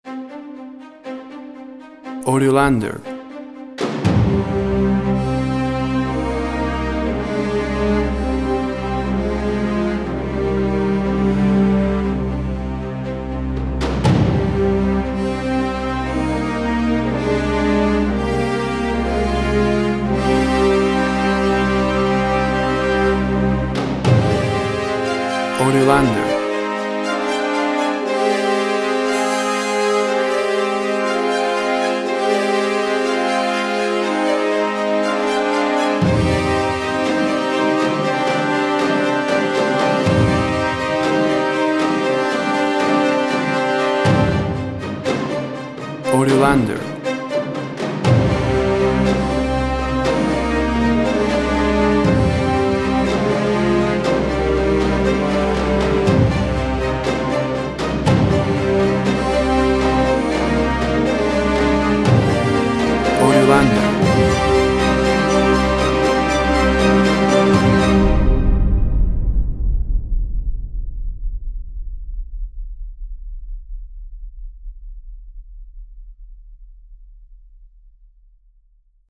Epic, Battle, Orchestal sounds.
WAV Sample Rate 24-Bit Stereo, 44.1 kHz
Tempo (BPM) 120